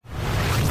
lasercharge2.mp3